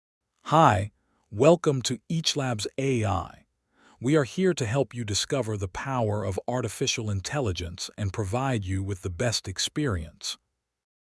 Créez une voix naturelle de qualité studio à partir de texte avec des choix de voix flexibles et un contrôle précis de la vitesse pour les doublages, les livres audio et les annonces.
text-to-spech-output.wav